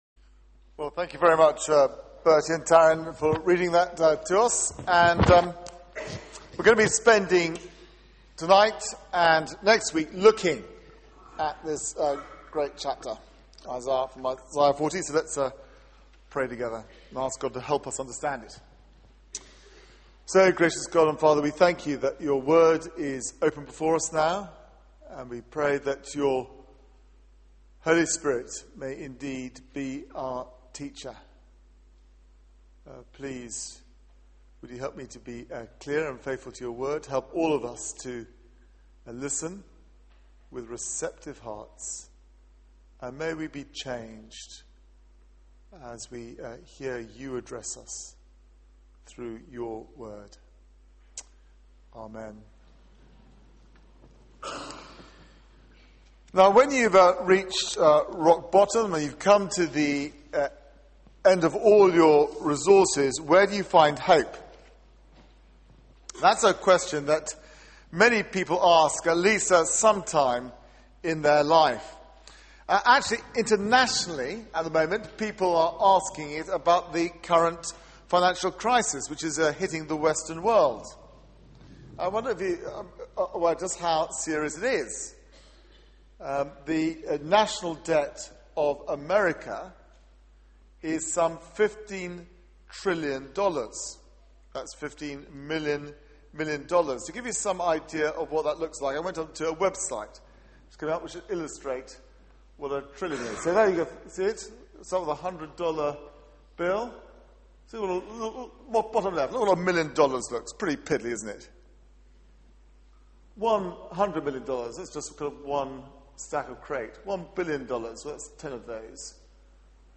Media for 6:30pm Service on Sun 27th Nov 2011 18:30 Speaker
Series: Behold your God Theme: The loving shepherd Sermon